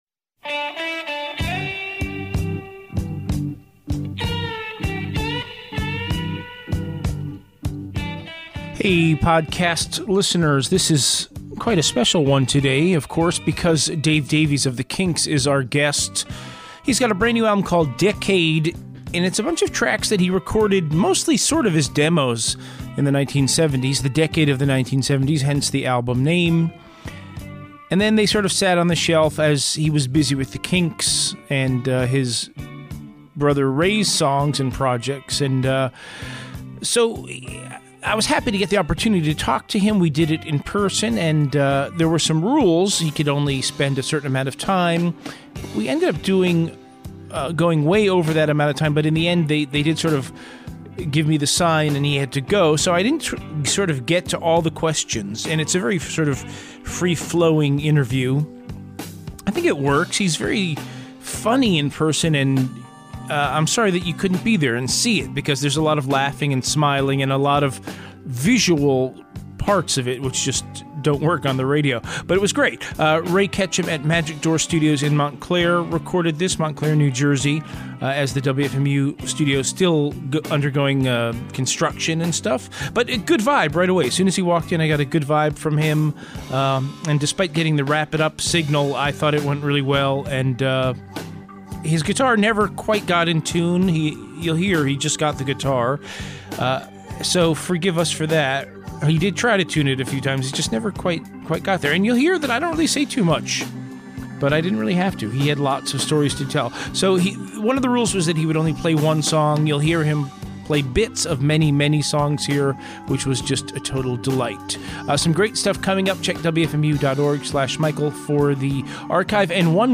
Dave Davies - "Interview"